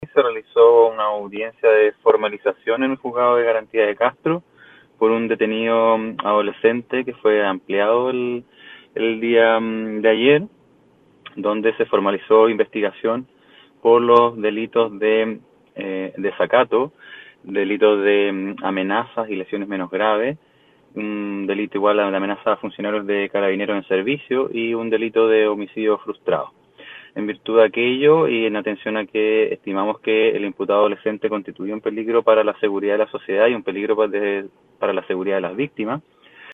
04-FISCAL-LUIS-BARRIA-.mp3